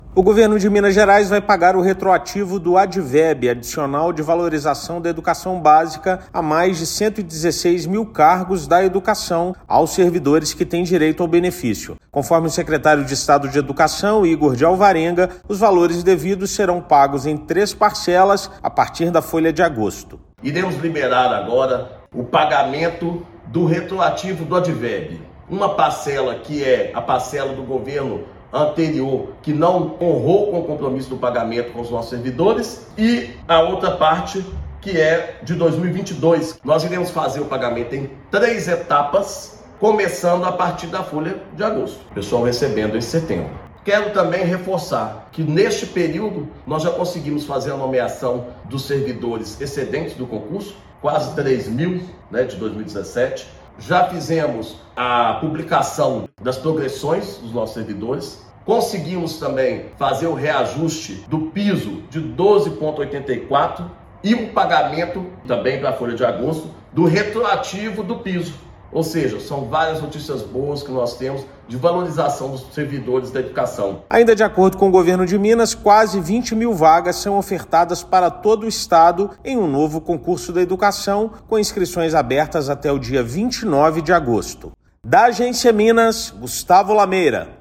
Servidores contemplados receberão a partir da folha de agosto, a ser paga em setembro. Ouça matéria de rádio.